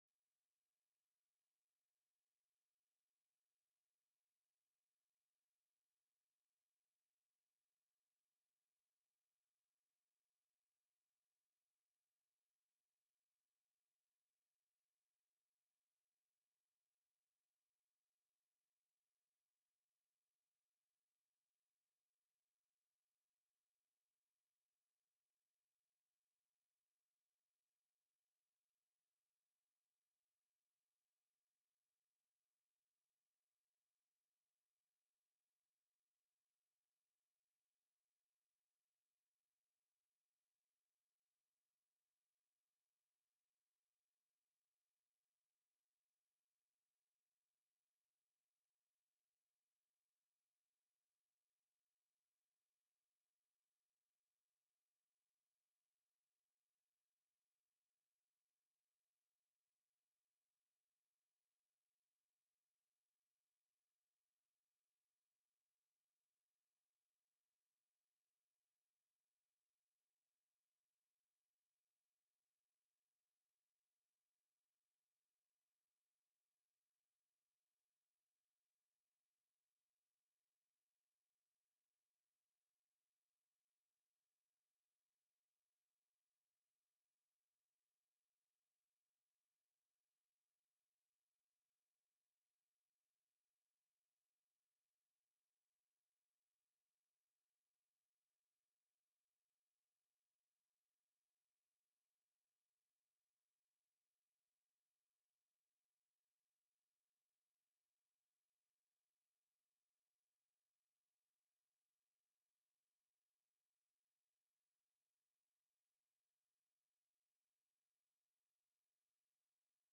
The audio recordings are captured by our records offices as the official record of the meeting and will have more accurate timestamps.
Presentation: Alaska Permanent Fund Corporation TELECONFERENCED